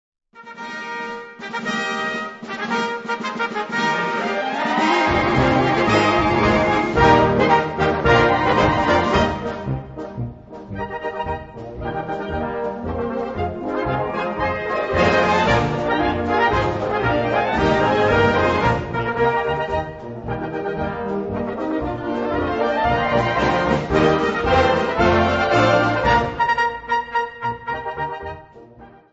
Categorie Harmonie/Fanfare/Brass-orkest
Subcategorie Concertmars
Bezetting Ha (harmonieorkest)